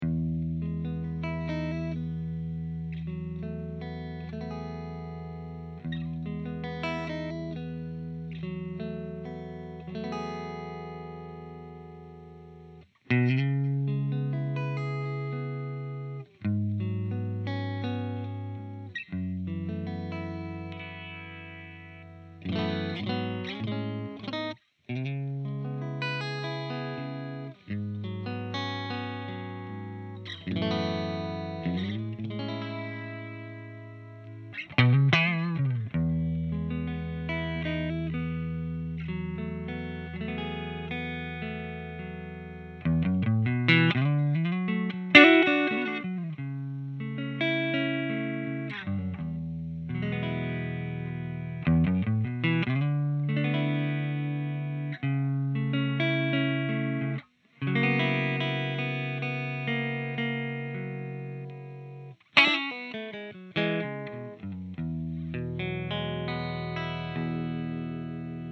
Clean riff 2